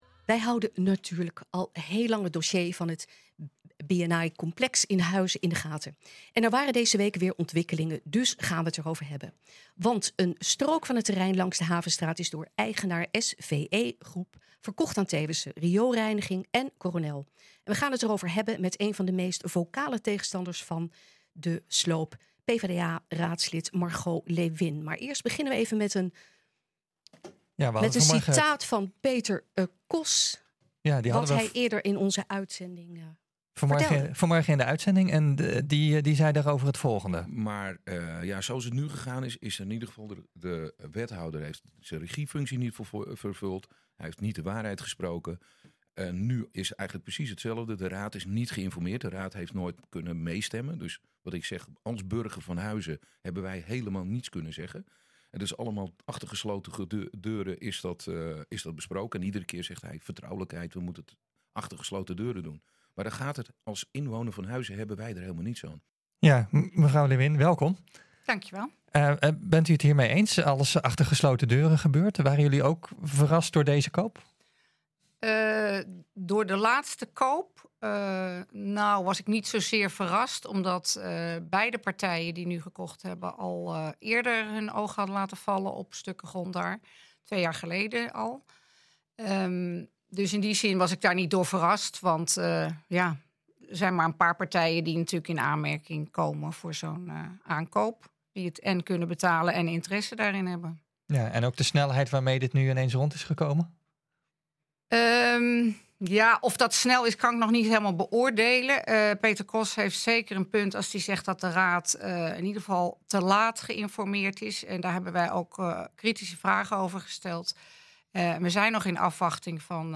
Want een strook van het terrein langs de Havenstraat is door eigenaar SVE Group verkocht aan Teeuwissen Rioolreiniging en Coronel. We gaan het erover hebben met een van de meest vocale tegenstanders van de sloop, PvdA-raadslid Margot Leeuwin.